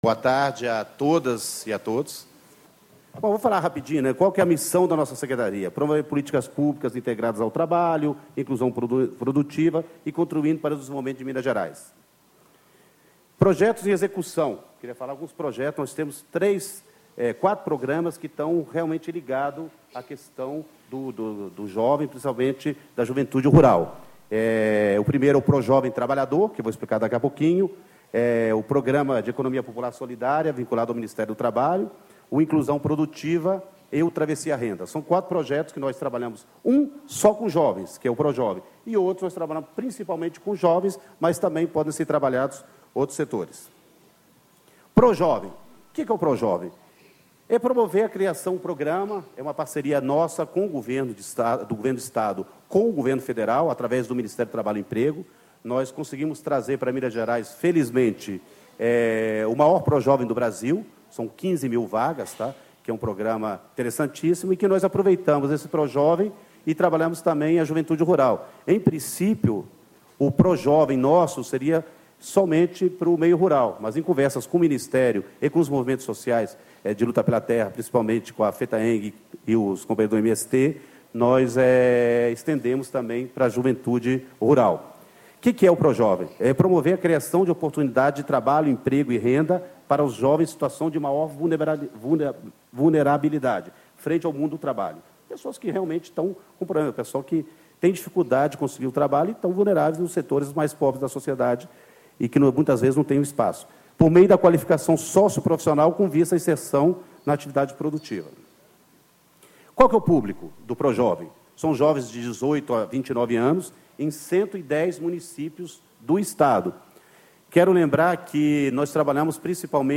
Hélio Augusto Martins Rabelo, Secretário de Estado de Trabalho e Emprego de Minas Gerais. Painel: Educação do Campo e Juventude
Discursos e Palestras